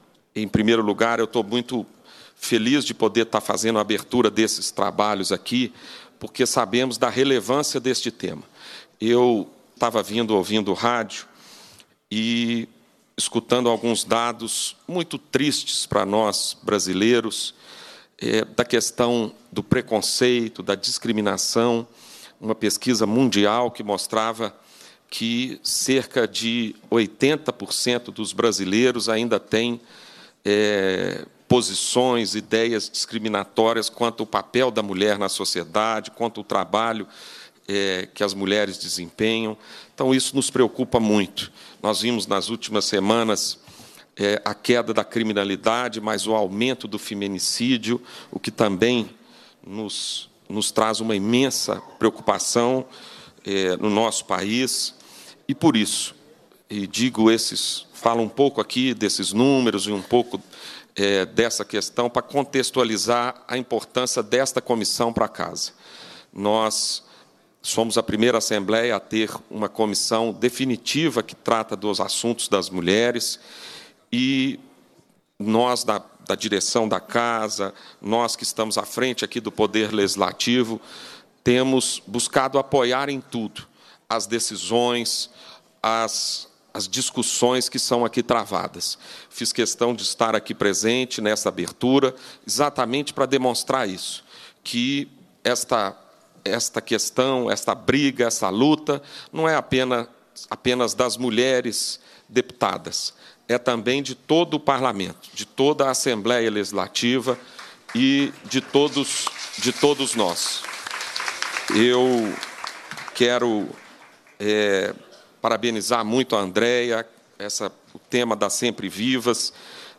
A diversidade do universo feminino foi exaltada, nesta sexta-feira (6), na Assembleia Legislativa de Minas Gerais, durante solenidade de abertura da Semana de Comemoração ao Dia Internacional da Mulher.